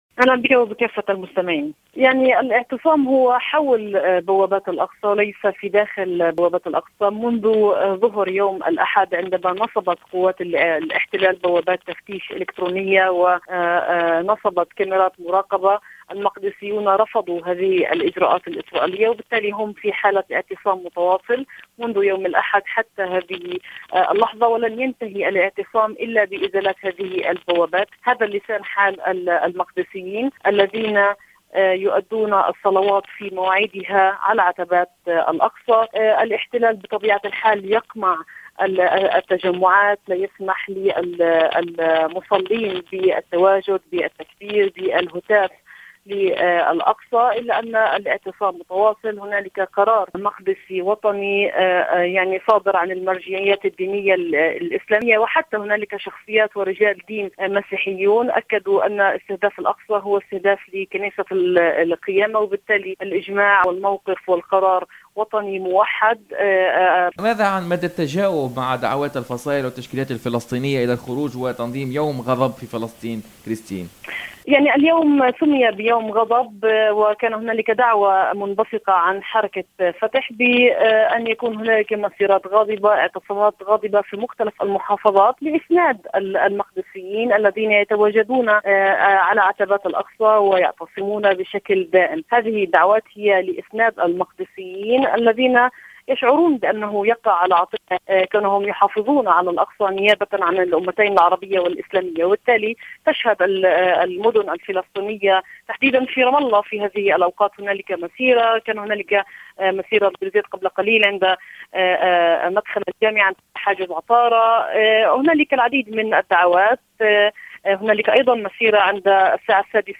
في اتصال هاتفي مع "الجوهرة أف أم" من القدس